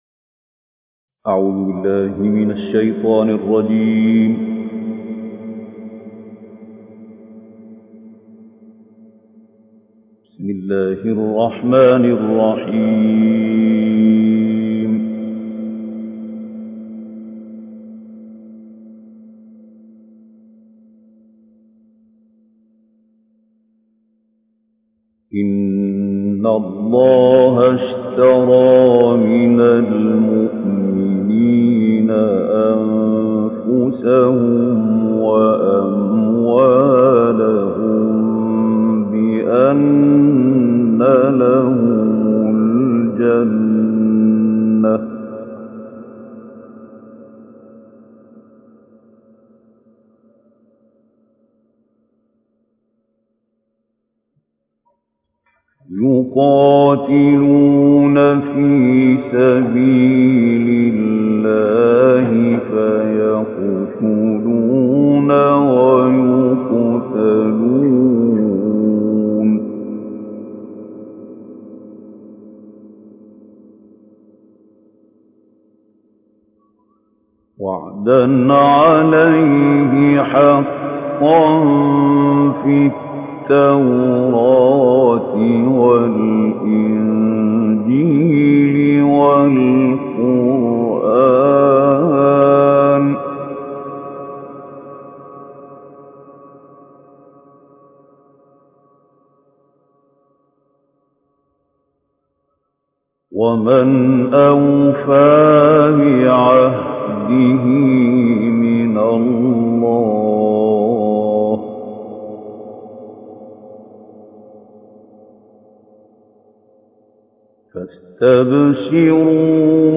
Reciter Mahmoud Khaleel El Hussary